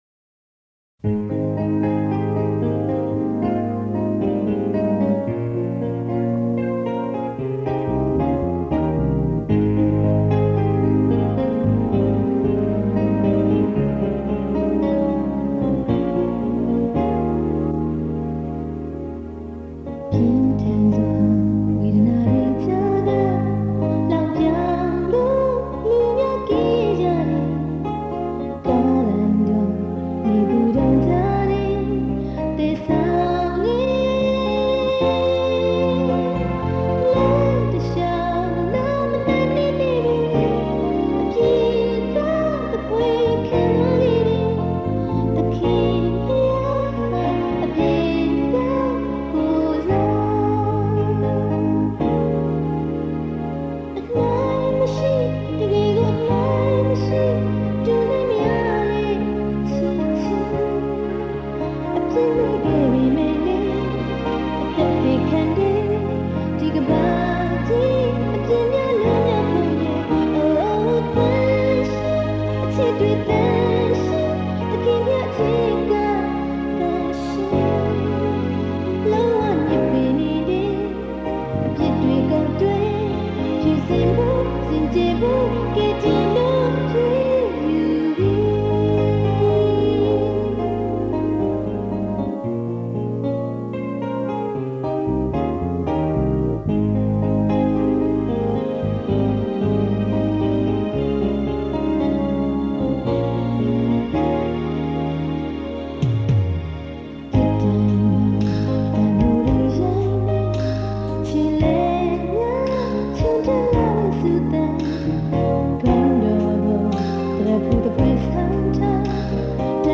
ခရစ်ယာန် ဓမ္မတေးများ